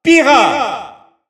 Announcer pronouncing Pyra's name in French.
Pyra_French_Announcer_SSBU.wav